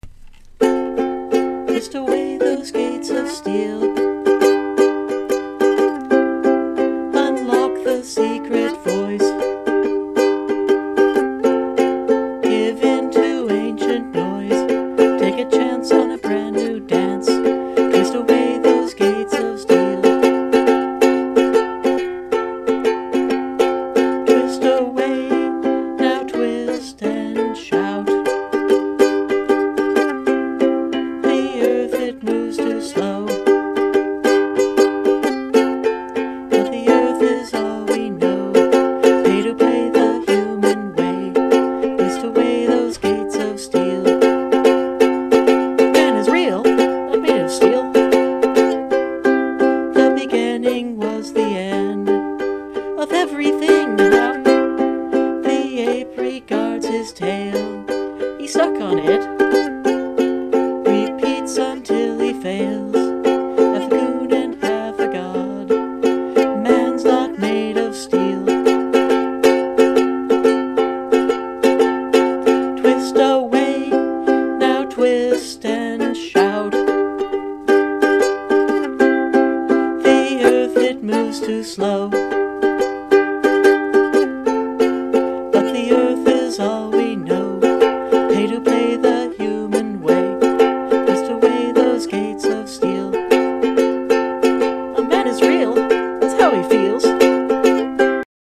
Sort of a mellow mood today.